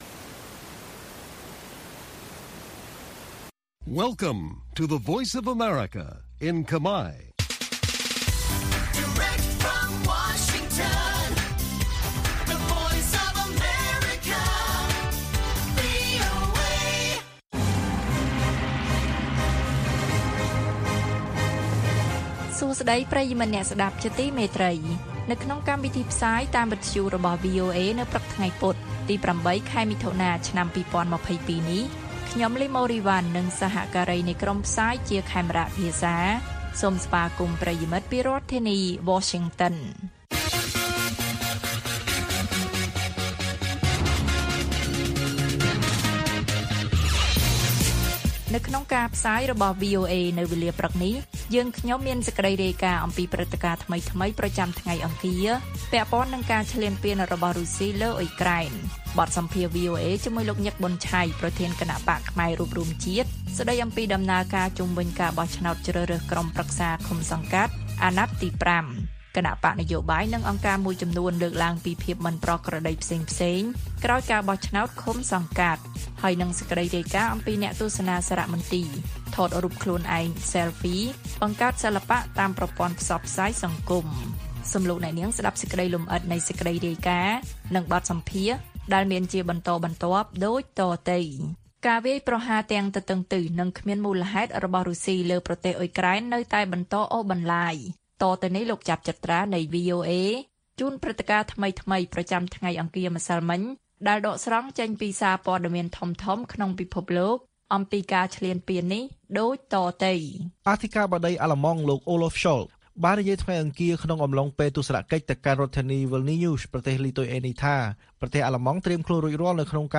ព័ត៌មានពេលព្រឹក ៨ មិថុនា៖ បទសម្ភាសន៍ VOA អំពីគណបក្សខ្មែររួបរួមជាតិថា លទ្ធផលទទួលបានខុសពីការរំពឹងទុកឆ្ងាយ ក៏ប៉ុន្តែនៅតែទទួលយក